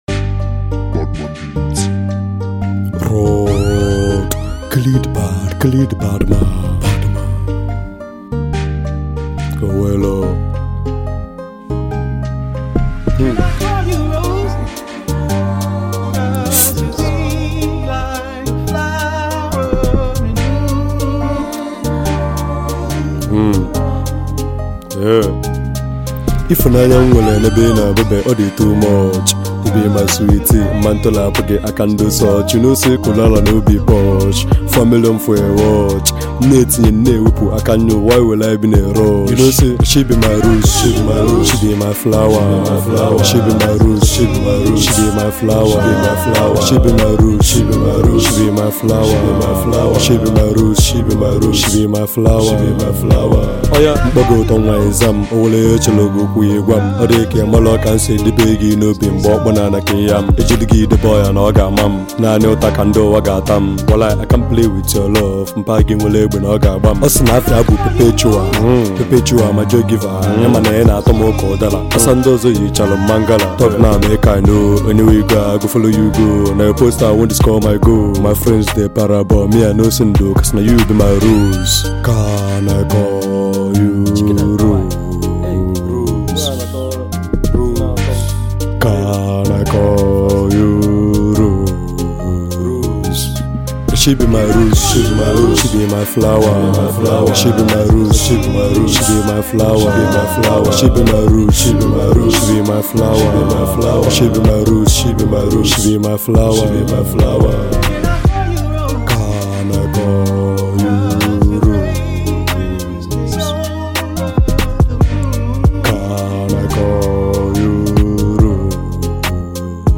Prominent Nigerian Singer, and Songwriter